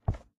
Minecraft Version Minecraft Version snapshot Latest Release | Latest Snapshot snapshot / assets / minecraft / sounds / item / book / close_put2.ogg Compare With Compare With Latest Release | Latest Snapshot
close_put2.ogg